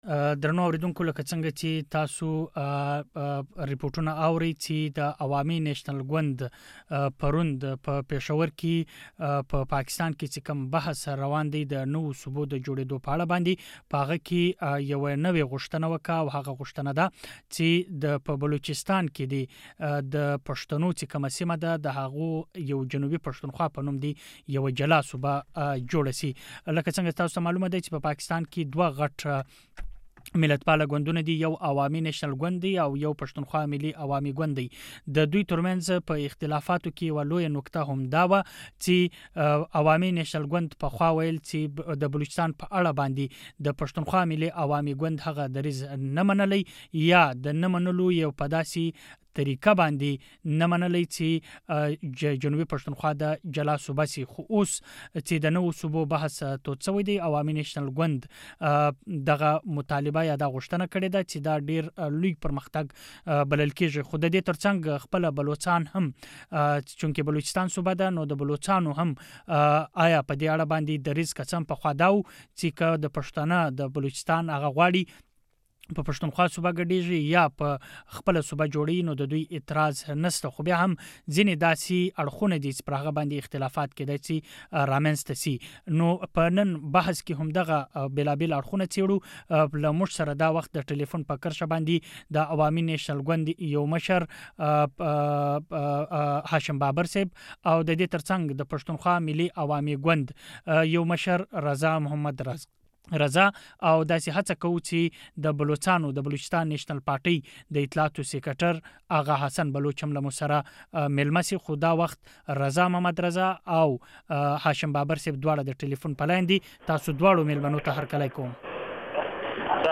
د مشال تر رڼا لاندې بحث